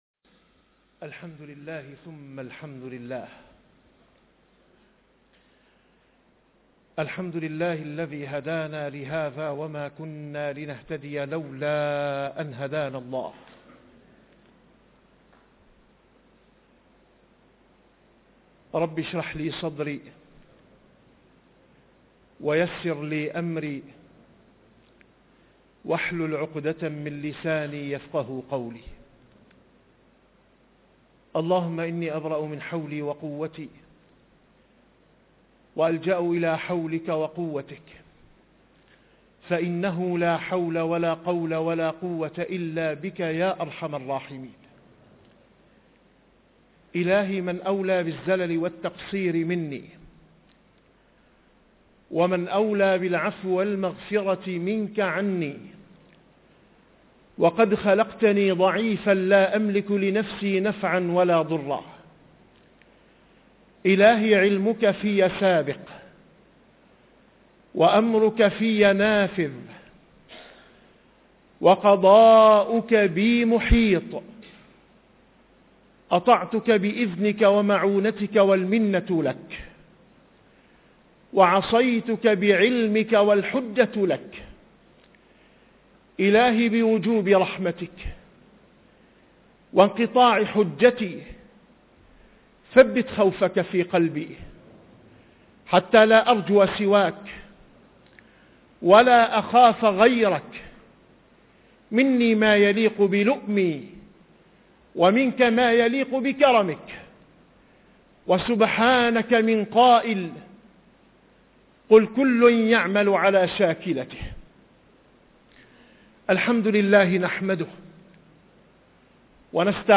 - الخطب -